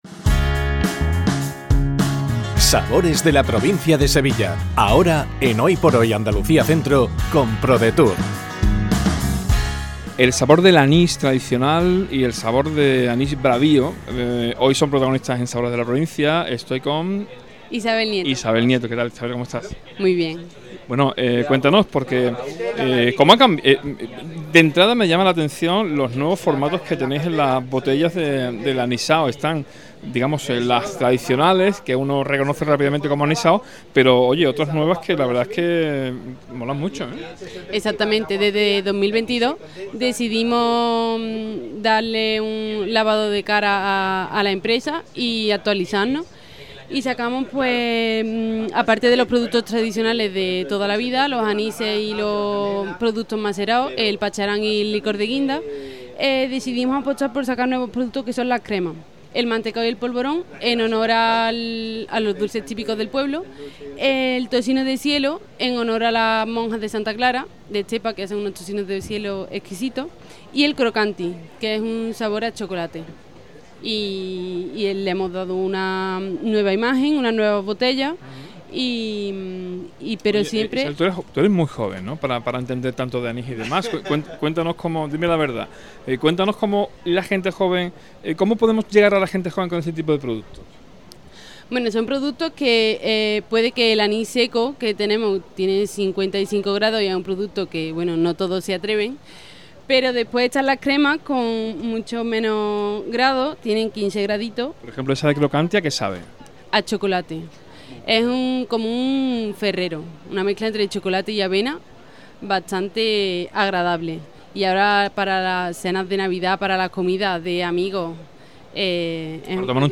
ENTREVISTA | ANIS BRAVIO